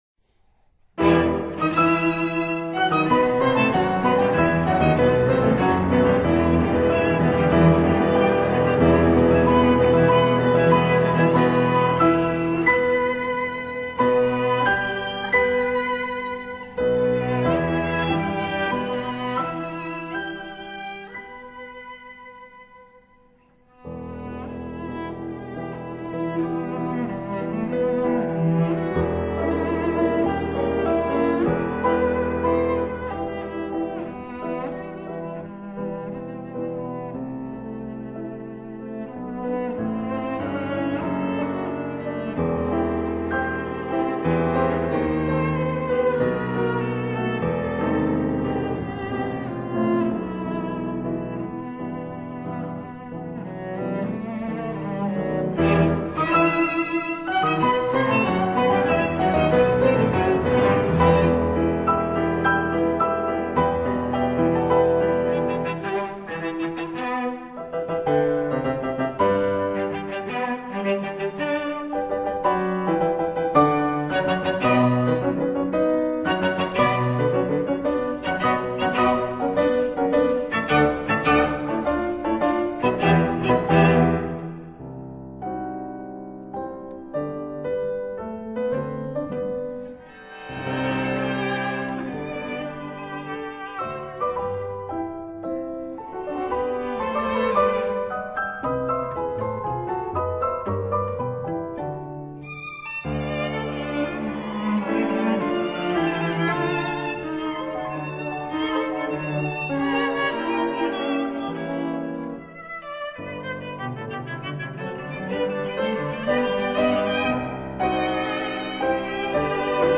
Piano Quartet in e minor
The opening movement, Allegro, begins with a powerful unison passage after which the cello then develops it along with with a syncopated accompaniment in the piano. The mood is quite intense although the tension is occasionally relaxed by tender and lyrical moments..